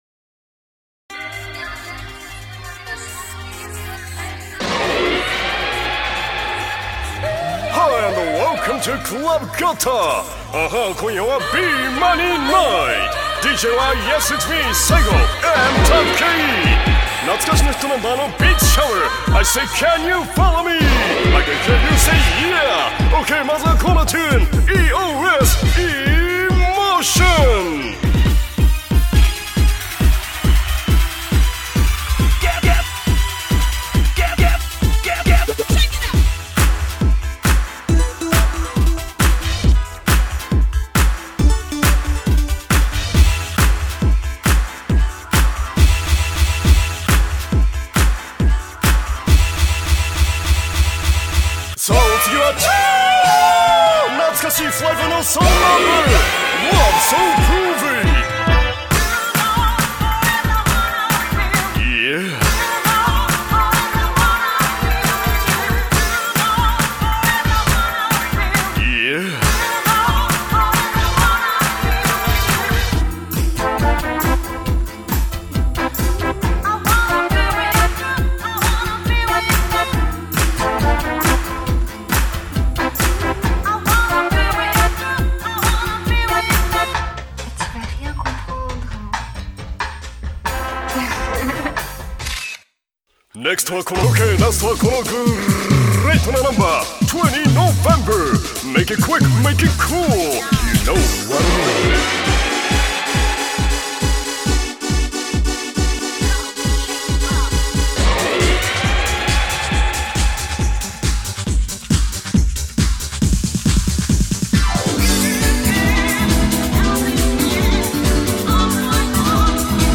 BPM137
Audio QualityCut From Video